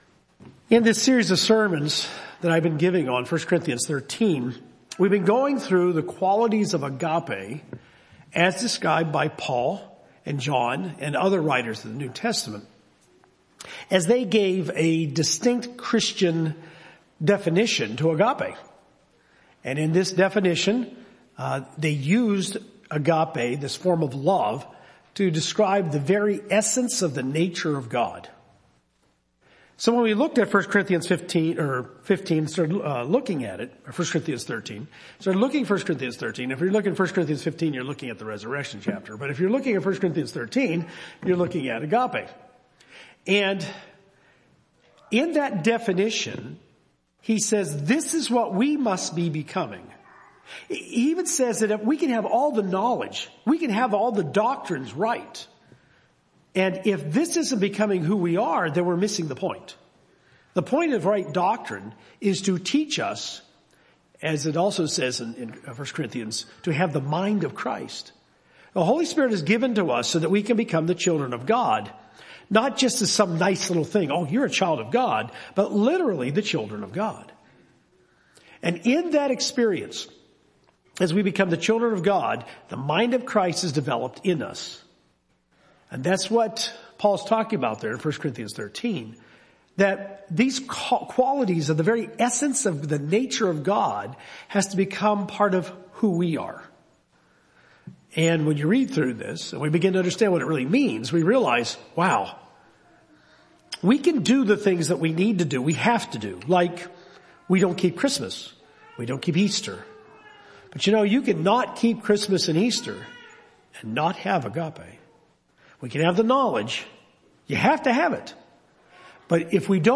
1 Corinthians 13 says that agape love does not envy. This sermon brings out three strategies for combatting envy in our lives.